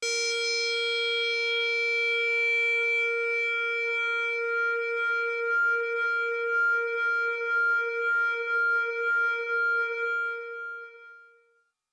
描述：通过Modular Sample从模拟合成器采样的单音。
标签： MIDI-速度-63 ASharp5 MIDI音符-82 挡泥板-色度北极星 合成器 单票据 多重采样
声道立体声